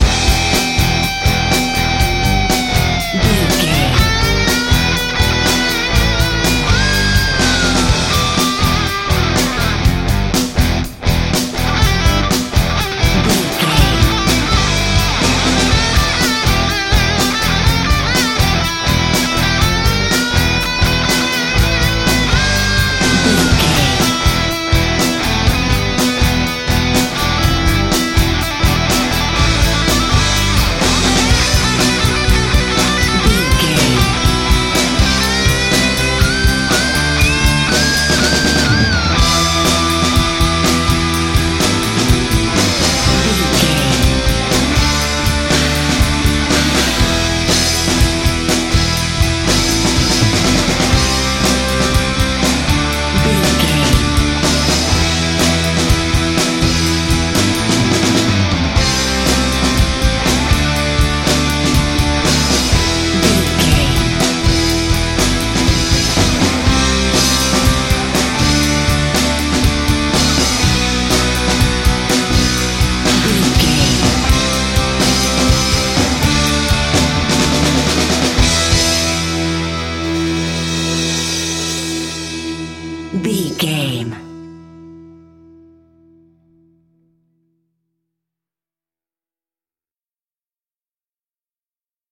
Aeolian/Minor
electric guitar
Sports Rock
hard rock
lead guitar
bass
drums
aggressive
energetic
intense
nu metal
alternative metal